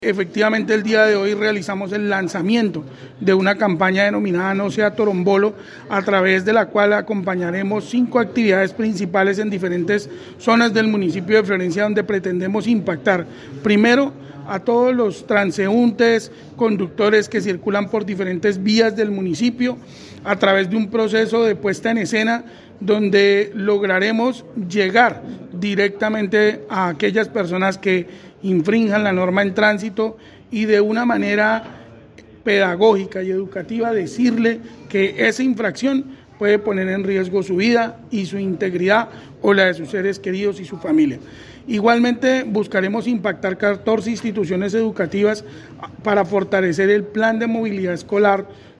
Jaime Becerra, secretario de transporte y movilidad de la ciudad, explicó que, durante los siguientes días, se empezarán a realizar una serie de acciones que redundarán en una mejor educación vial.